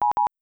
mcc7550_emerg.wav